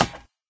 woodmetal3.ogg